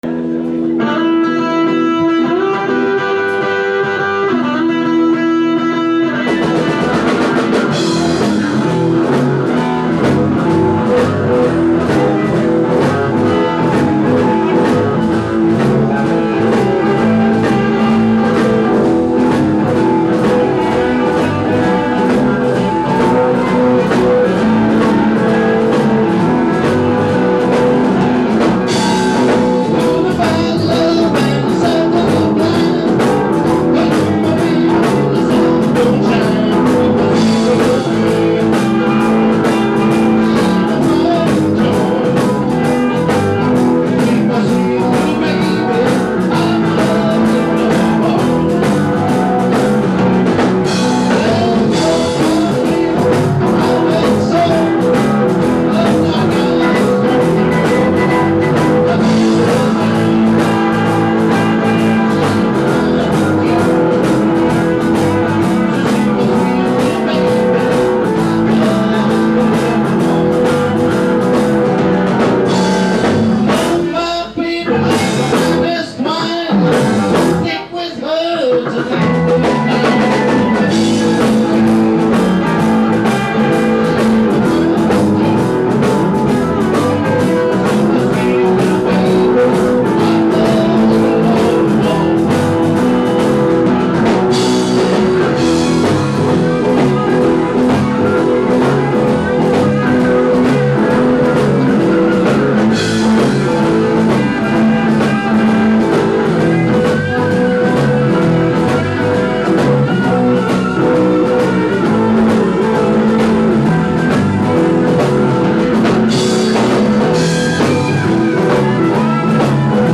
Please understand that not only were these outdoor audience recordings made under extremely difficult conditions, but also that much of the band had not even met before the party, let alone practiced together, and the jam lasted well under an hour.